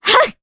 Artemia-Vox_Casting1_jp.wav